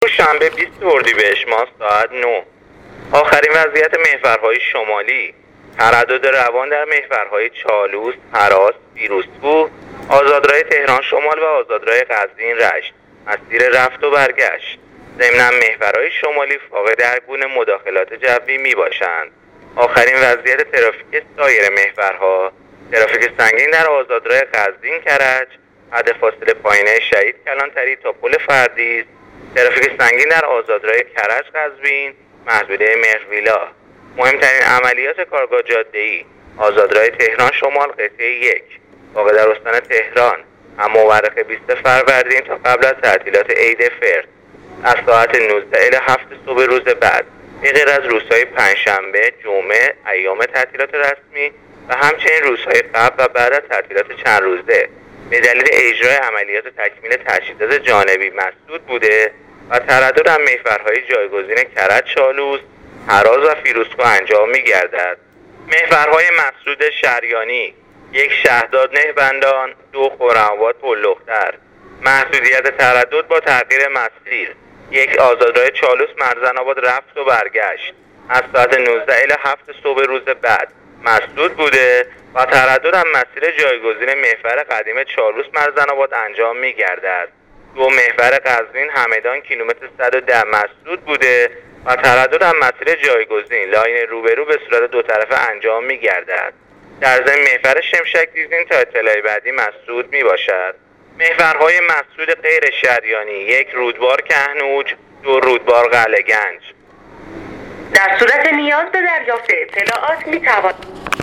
گزارش رادیو اینترنتی از آخرین وضعیت ترافیکی جاده‌ها تا ساعت ۹ بیست اردیبهشت ۱۳۹۹